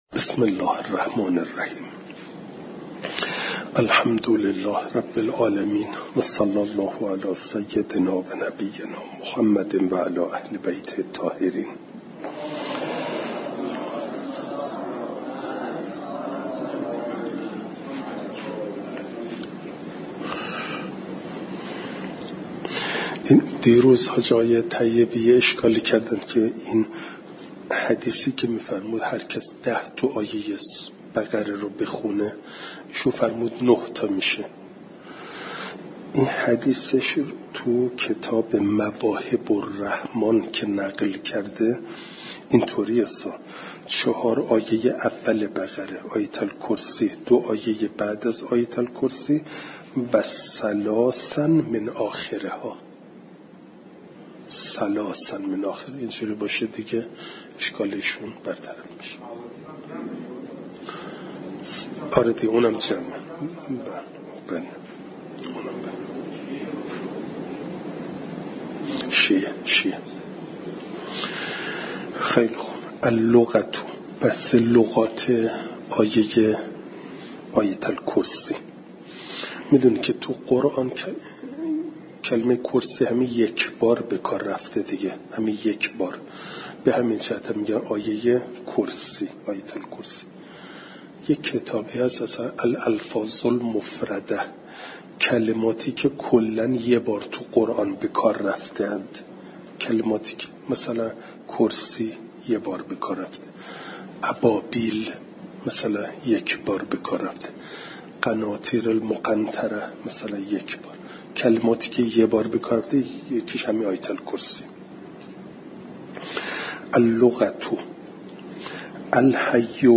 فایل صوتی جلسه دویست و سی ام درس تفسیر مجمع البیان